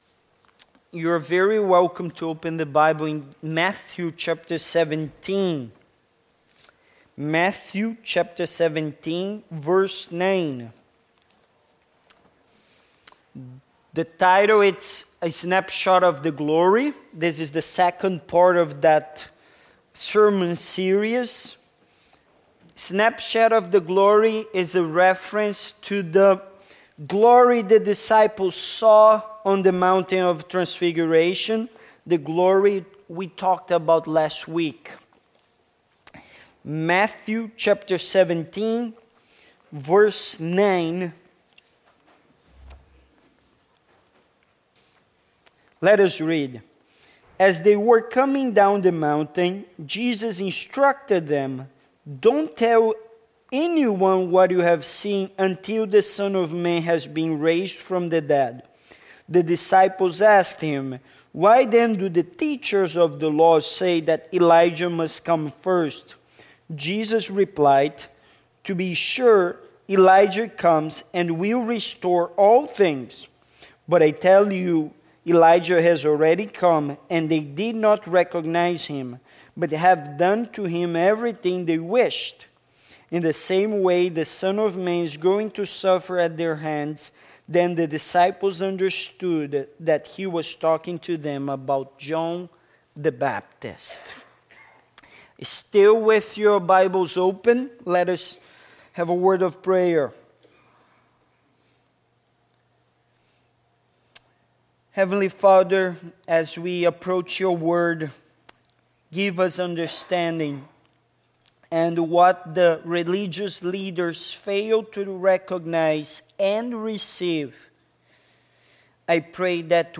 Passage: Matthew 17:9-13 Service Type: Sunday Morning